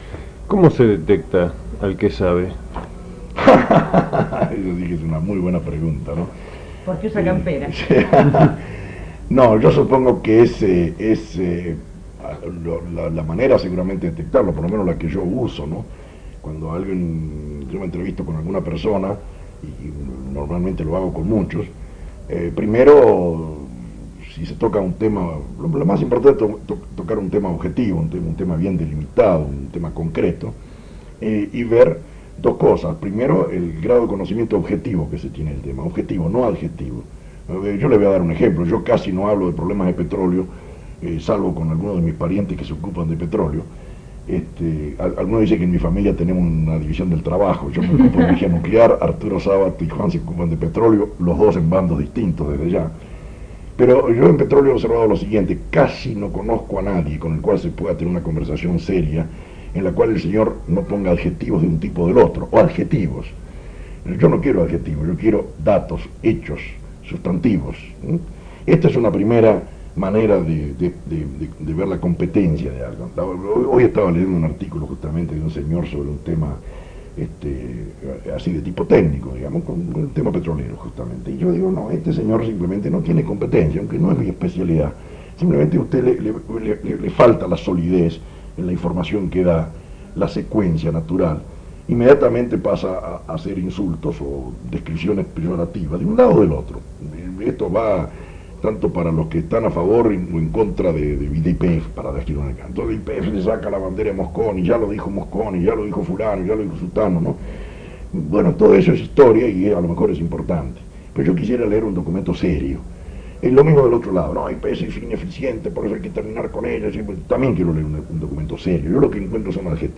Programa "Testimonios" - Radio Continental, transmitido el 16 de marzo de 1982.
ENTREVISTAS, CHARLAS Y CONFERENCIAS DE JORGE A. SABATO